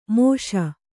♪ mōṣa